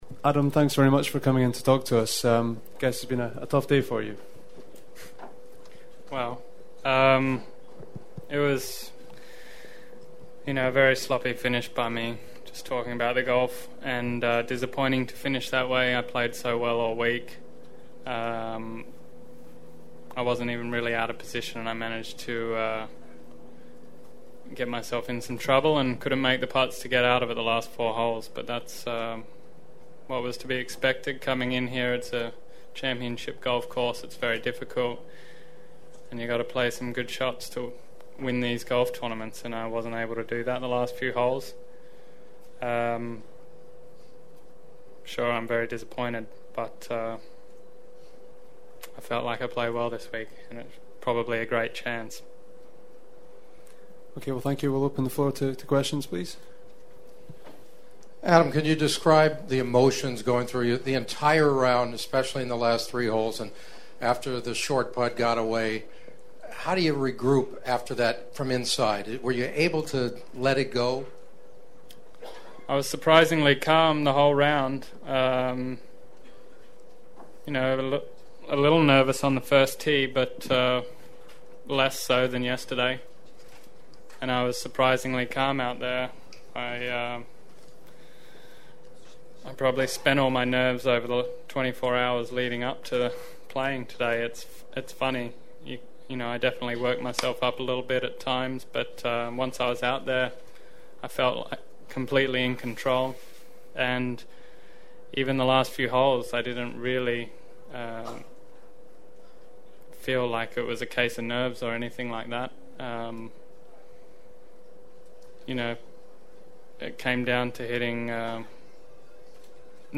Then we all remember Scott’s collapse in the final few holes that allowed his good buddy Ernie Els to take home the Claret Jug instead…and the following shows my difficult task of having to ask the guy who just walked off the 18th green, how it feels to choke away a major?